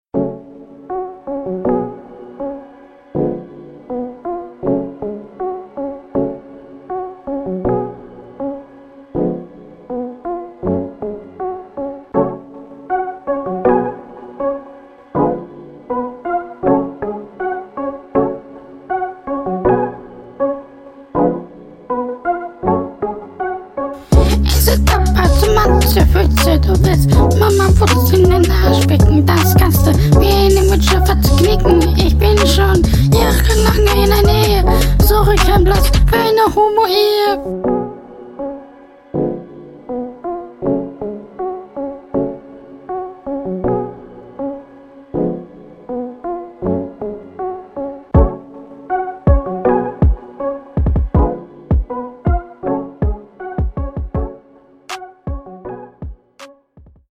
Hallo erst mal naja zu dir Stimme gepitcht find ich nicht so nice und hört …